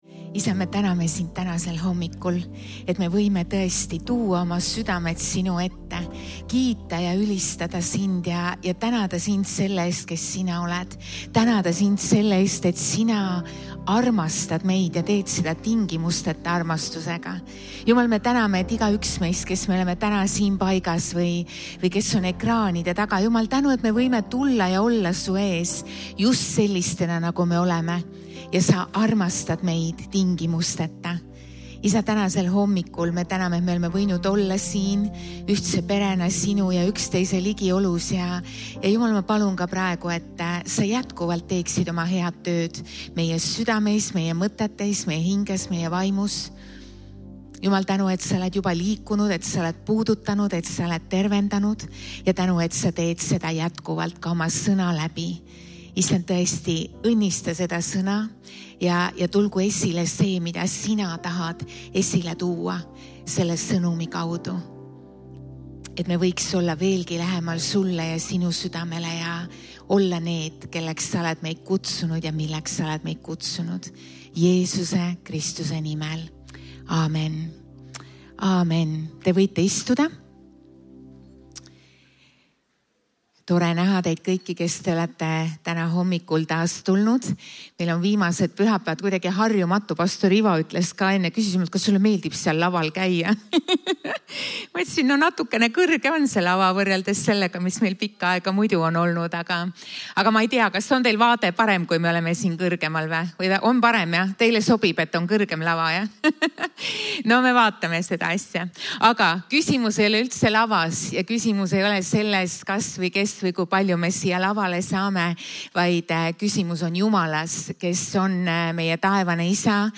Kristlik ja kaasaegne kogudus Tallinna kesklinnas.
12.01.2025 Jutlus - Armastage üksteist